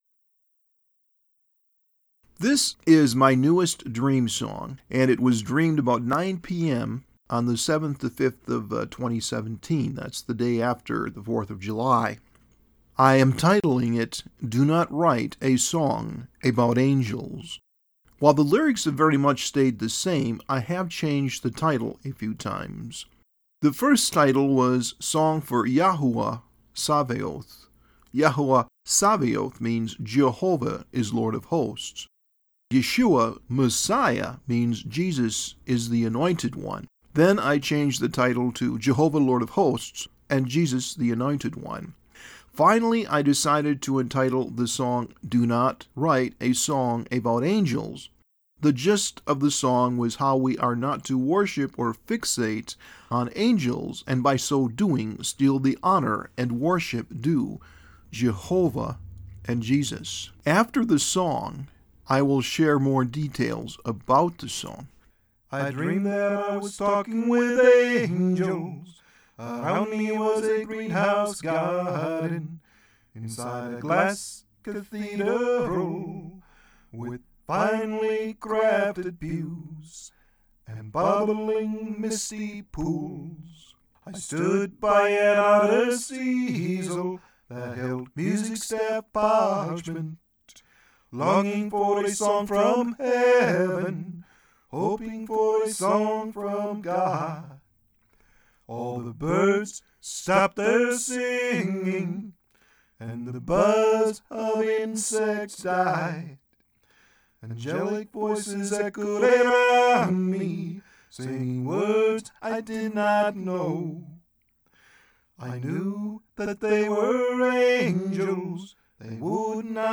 Volume enhanced version of Do Not Write a Song About Angels